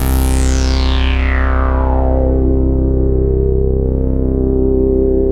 SYN_JD-800 1.1.wav